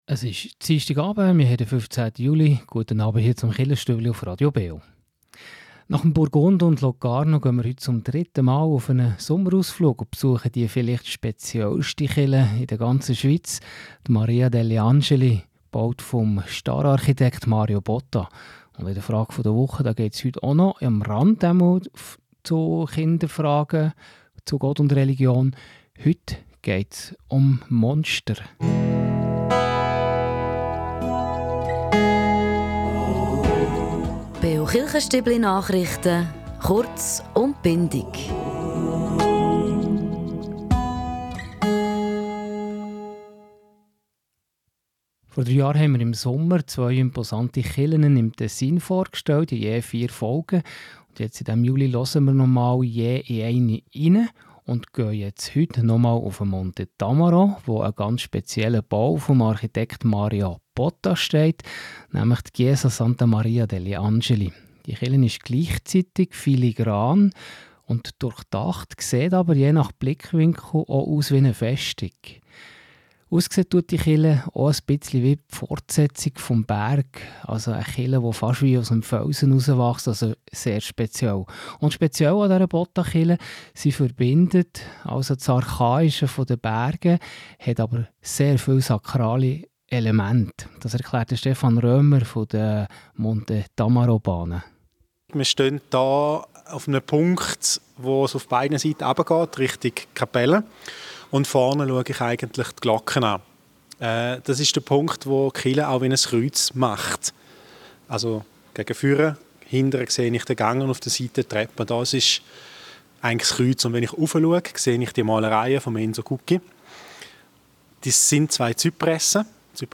Die Chiesa degli Angeli auf dem Monte Tamaro: Die ganze Reportage kann im Krichenfenster aus dem Jahr 2022 nachgehört werden.